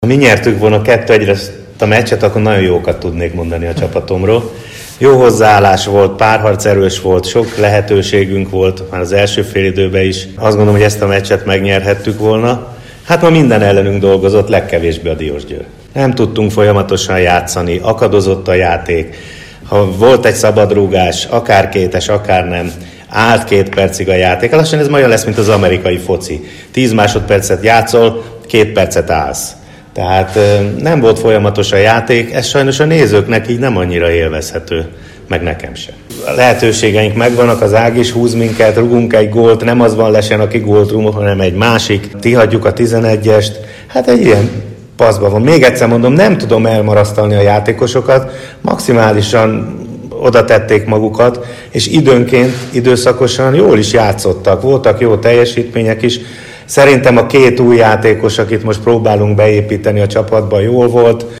A Fizz Liga 22. fordulójában a Paksi FC a DVTK együttesét fogadta, és 2:1 arányú vereséget szenvedett. Gól nélküli első félidő után a találkozó utolsó 10 percében született mindhárom találat. 2:0 után tudott szépíteni a Paks Gyurkits révén a 91.percben. Bognár György vezetőedző így értékelt a találkozó utáni sajtótájékoztatón.